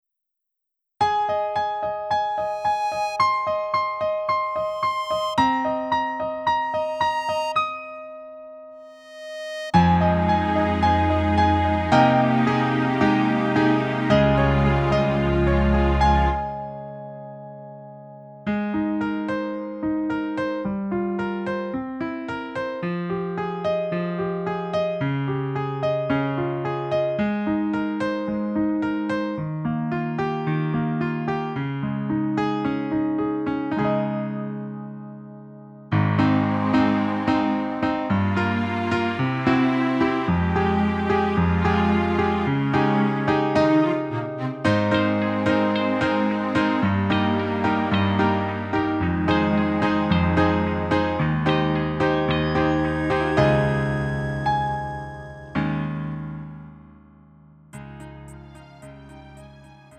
음정 원키 4:09
장르 가요 구분 Lite MR